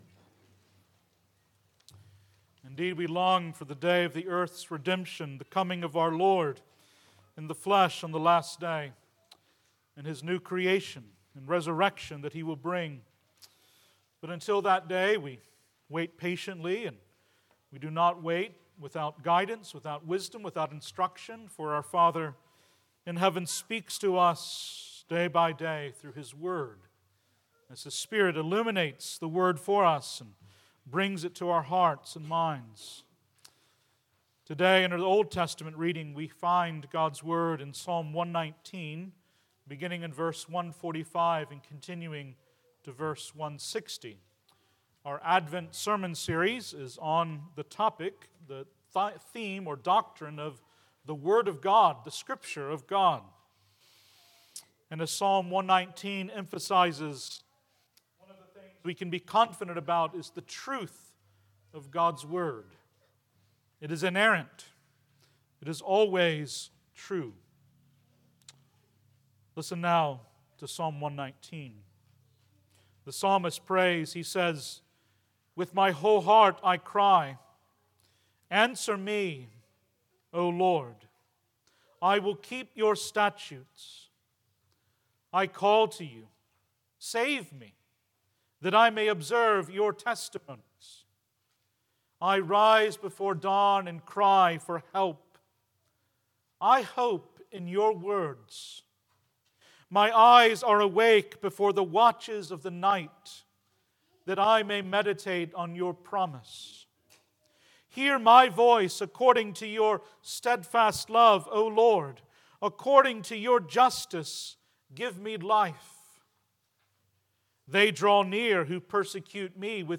Sermons – Page 3 – Colleyville Presbyterian Church
Service Type: Worship